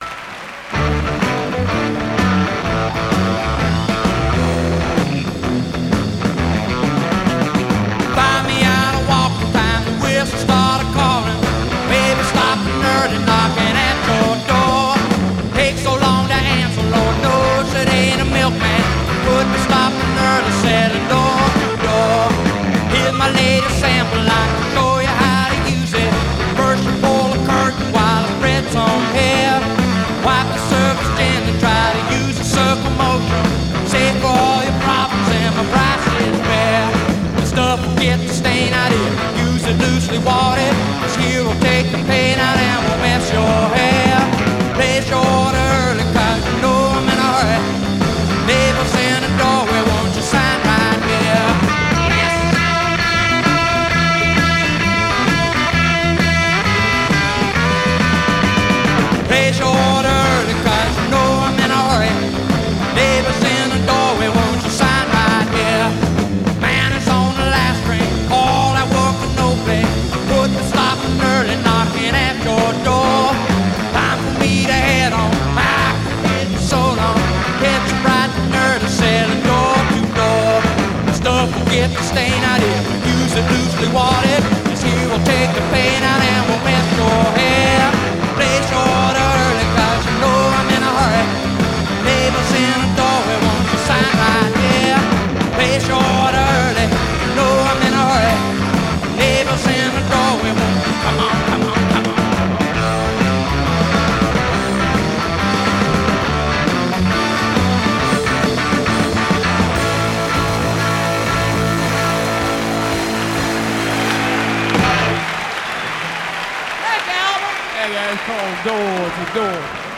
Жанр: Rock
Стиль: Classic Rock, Country Rock